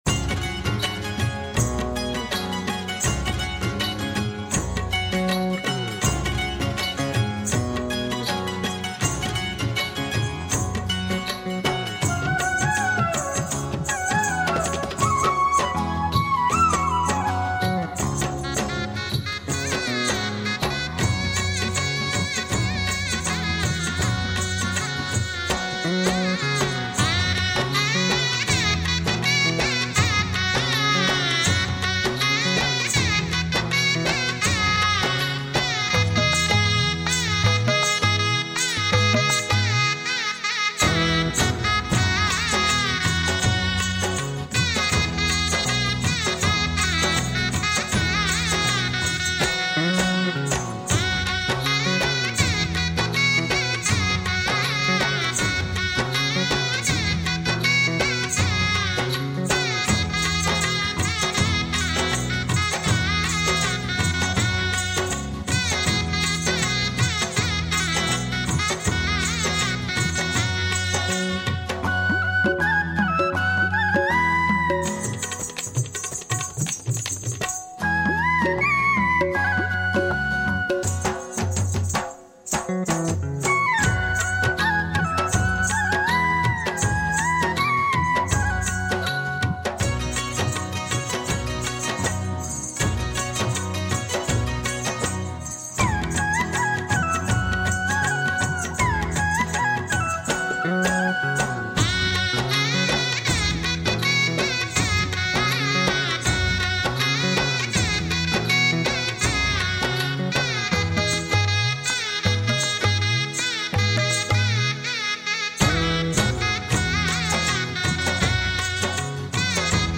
Rajasthani Vivah Geet Shehnai